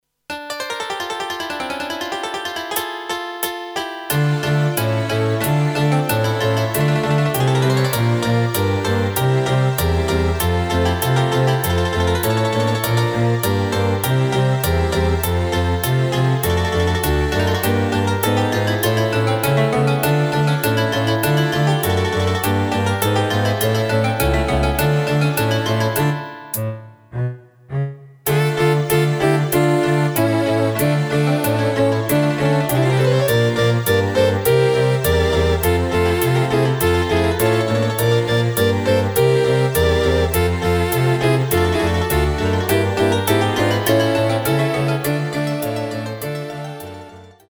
Rubrika: Národní, lidové, dechovka